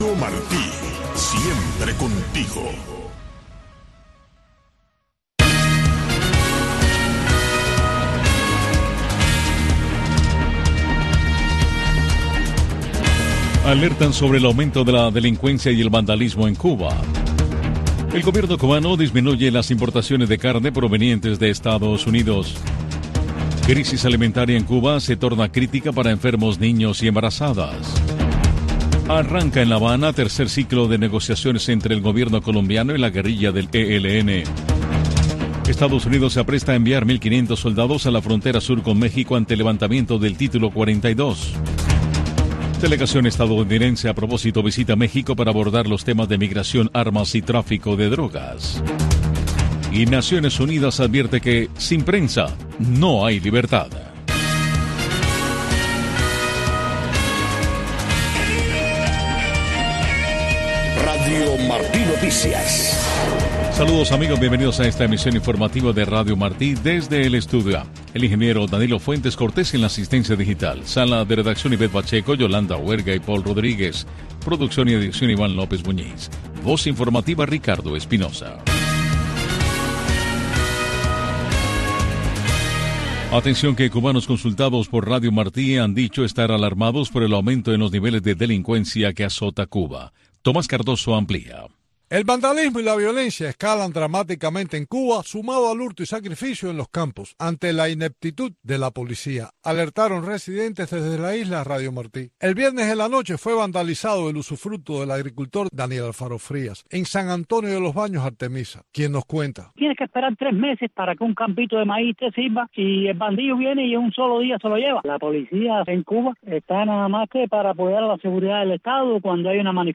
Noticiero de Radio Martí 3:00 PM